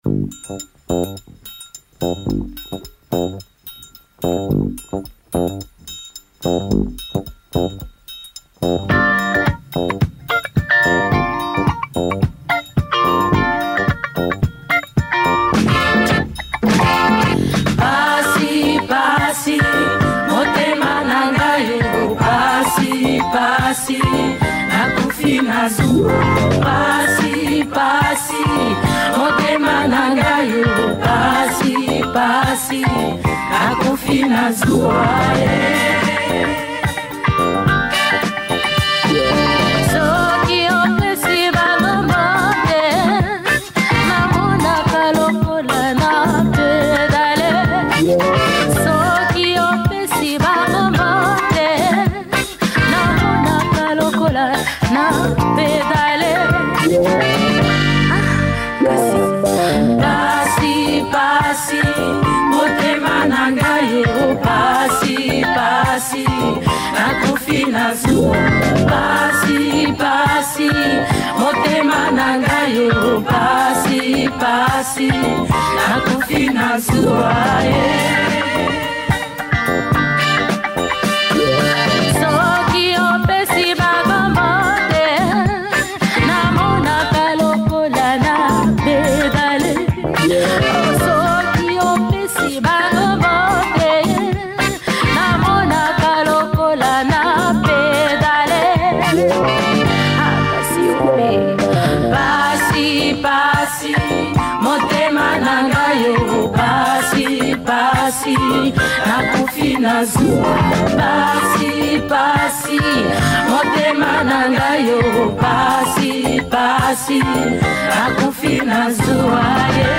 Heavy afro funk